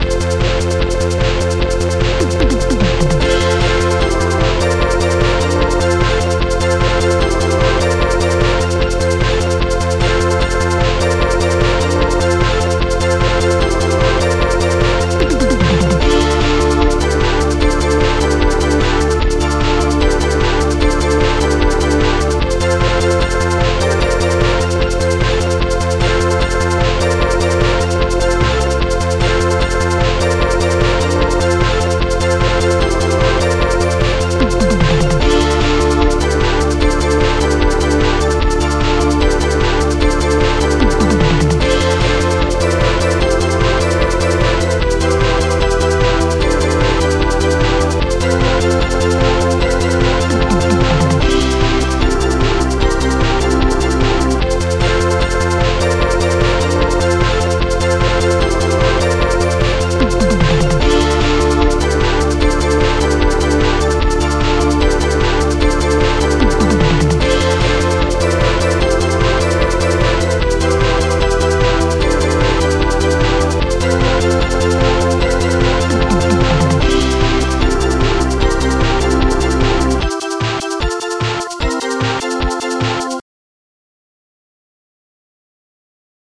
GOOD BASS DRUM HARD SNARE SHAKER DXTOM * SYNTH PLUCK